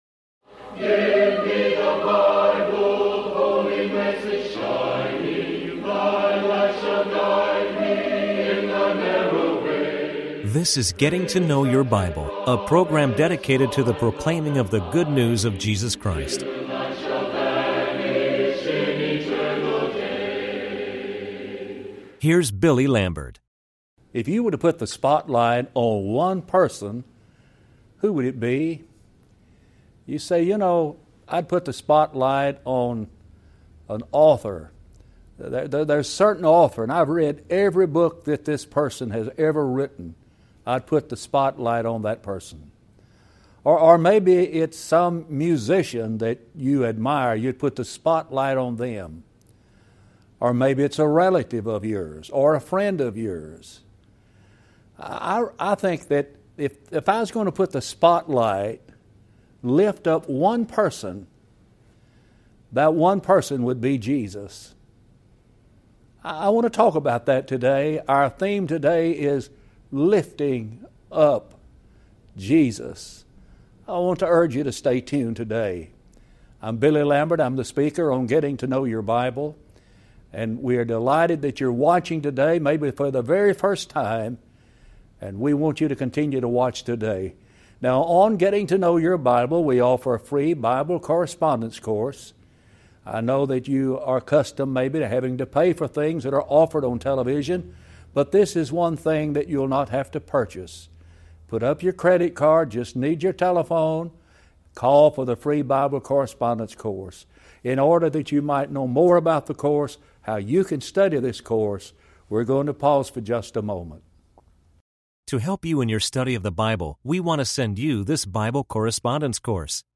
Talk Show Episode, Audio Podcast, Getting To Know Your Bible and Ep1365, Lifting Up Jesus on , show guests , about Lifting Up Jesus, categorized as History,Love & Relationships,Philosophy,Psychology,Religion,Christianity,Inspirational,Motivational,Society and Culture